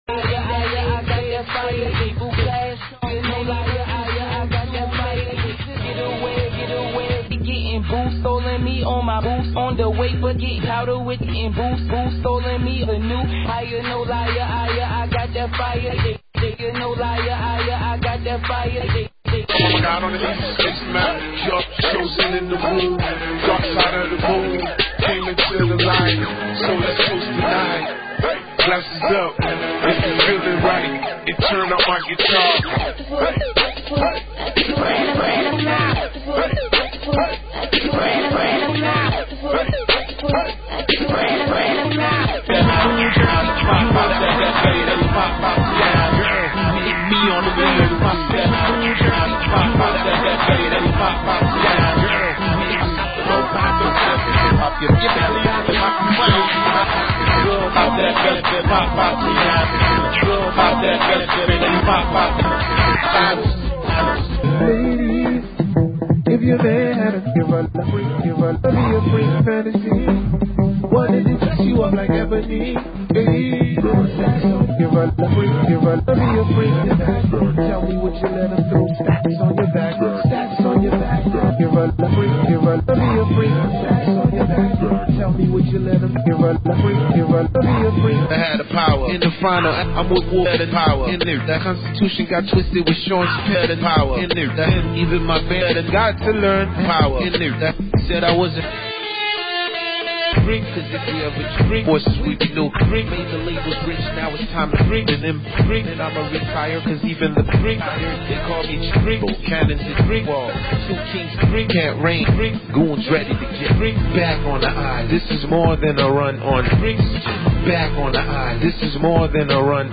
Reggae & Boost